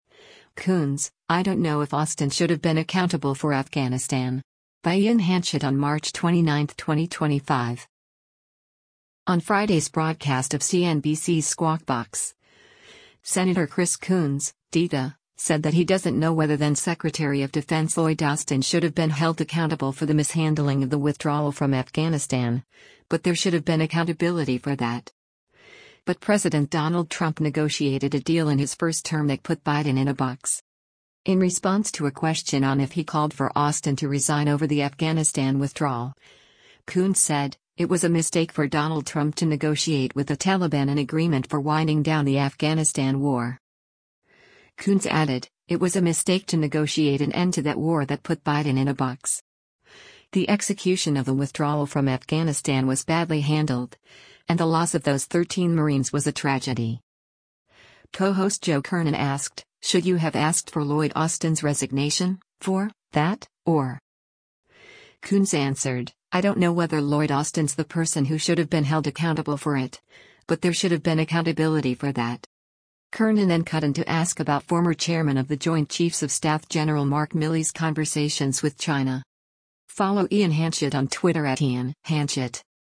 On Friday’s broadcast of CNBC’s “Squawk Box,” Sen. Chris Coons (D-DE) said that he doesn’t know whether then-Secretary of Defense Lloyd Austin should have been held accountable for the mishandling of the withdrawal from Afghanistan, “but there should have been accountability for that.”
Co-host Joe Kernen asked, “Should you have asked for Lloyd Austin’s resignation [for] that, or?”